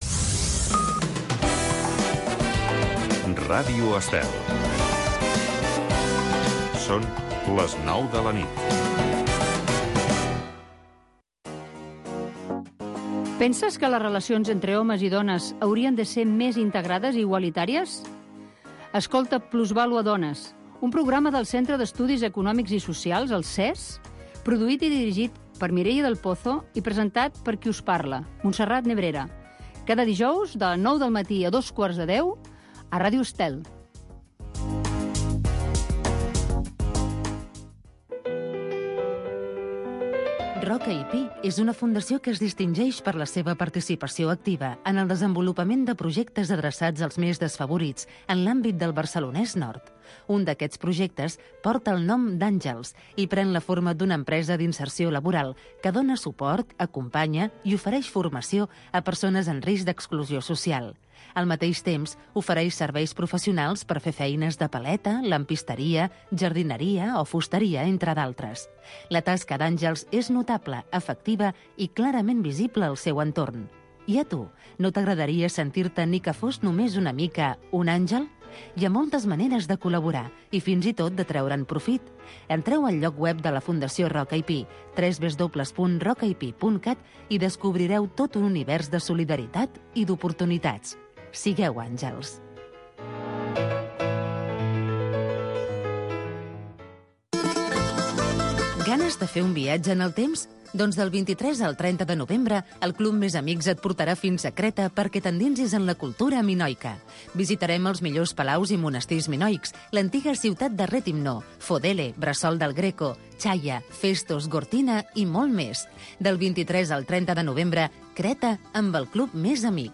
Oh la la, la musique. Programa de música francesa.